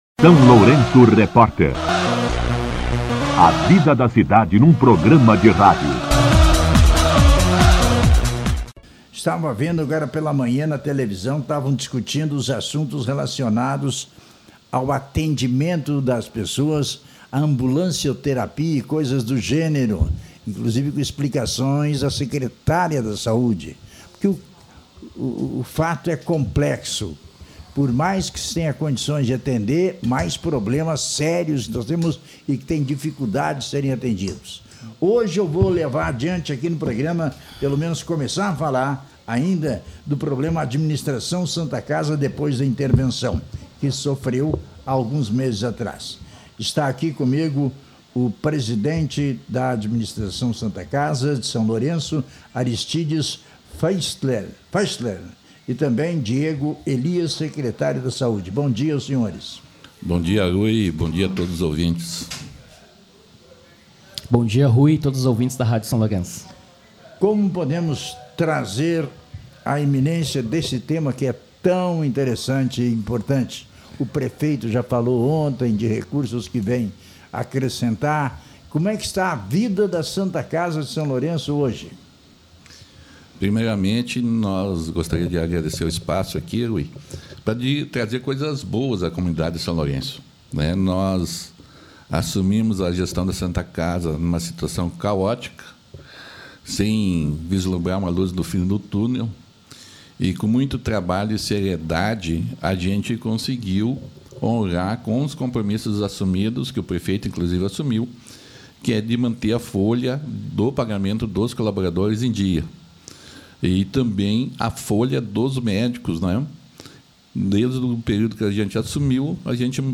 Entrevista com O secretário de Saúde